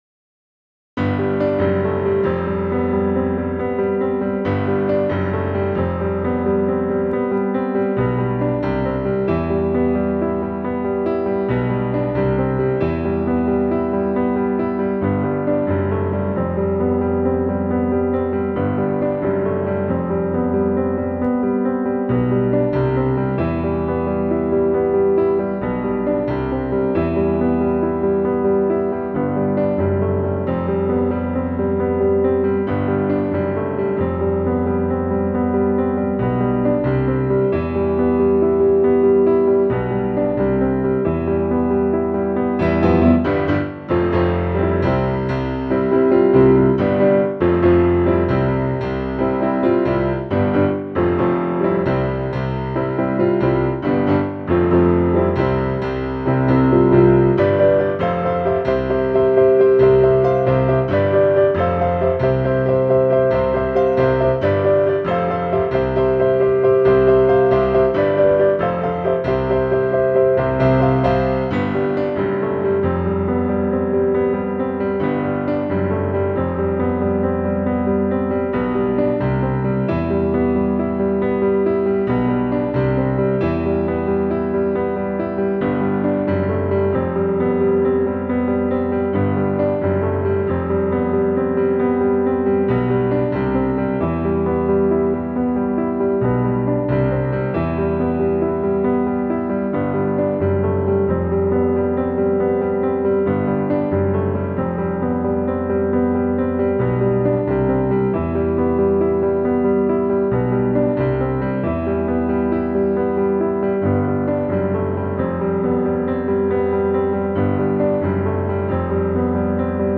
Faith Piano 1.wav